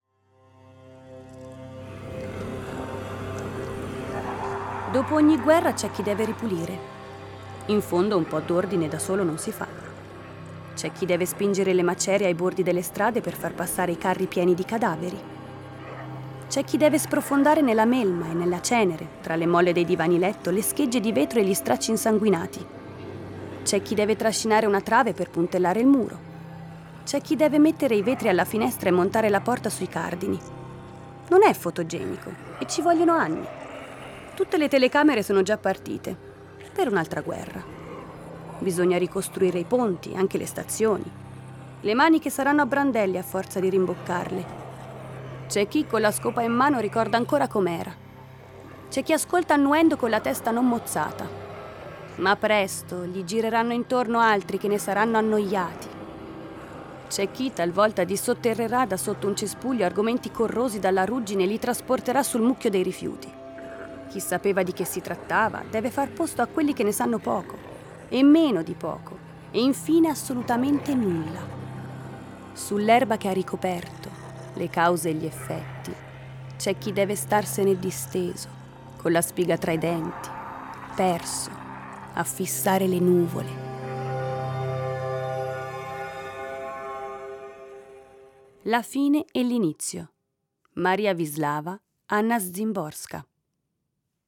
Letto da